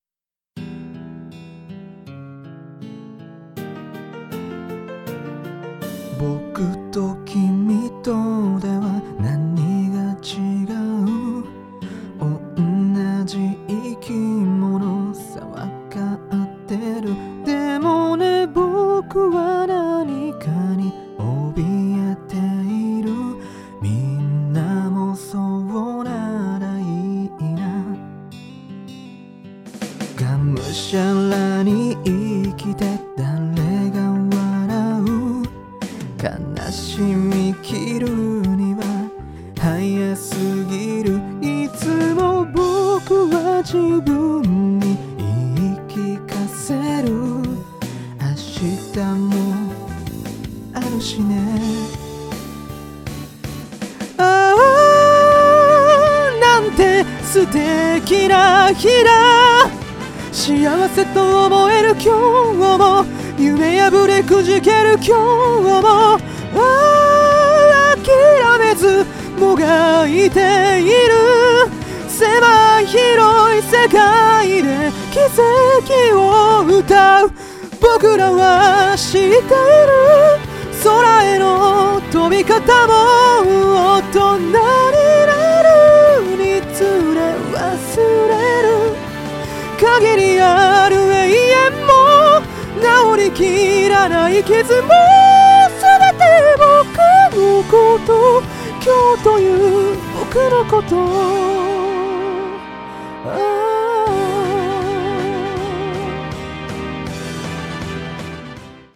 講師の歌唱音源付き記事
音量注意！
※カラオケ音源はこちらからお借りしました。
この曲は最高音G5という女性歌手の楽曲でも珍しいくらいの超高音です。